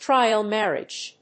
アクセントtríal márriage